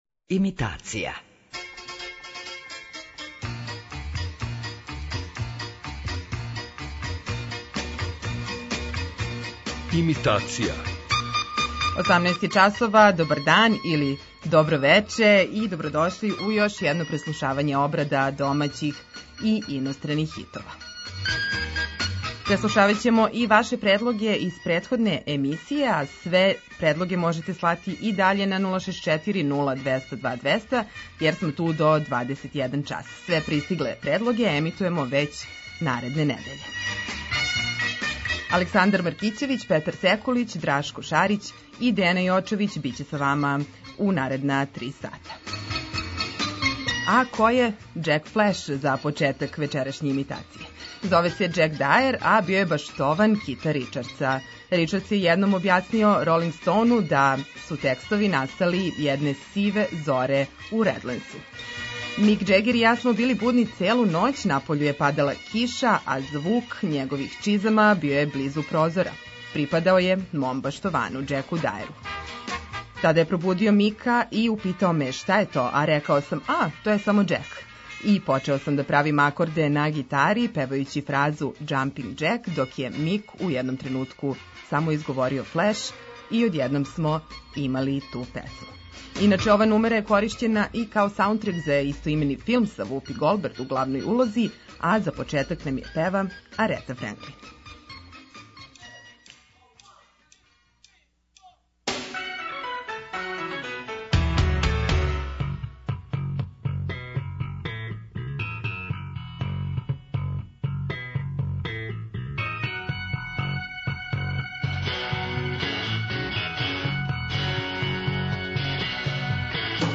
Имитација је емисија у којој емитујeмо обраде познатих хитова домаће и иностране музике.